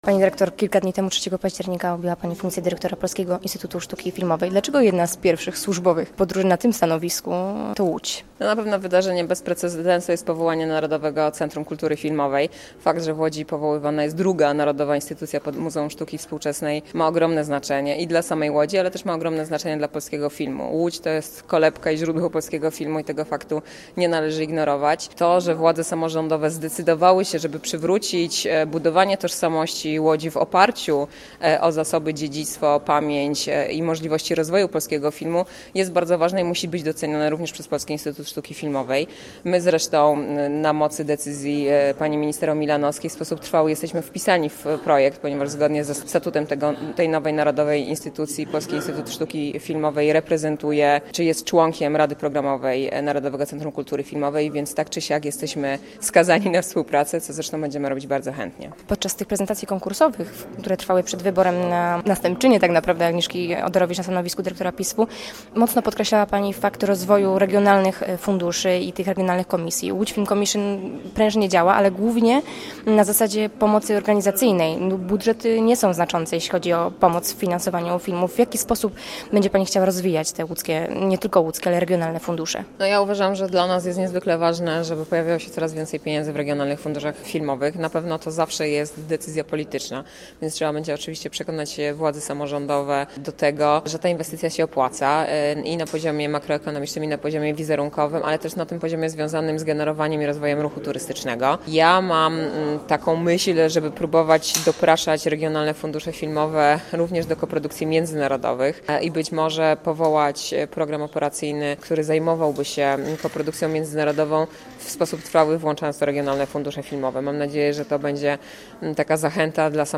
Nowa dyrektor PISF Magdalena Sroka: "Łódź to kolebka filmu" [ROZMOWA] - Radio Łódź
Posłuchaj całej rozmowy z Magdaleną Sroką: Nazwa Plik Autor Rozmowa z Magdaleną Sroką, nową dyrektorką PISF audio (m4a) audio (oga) Warto przeczytać Pogoda na wtorek.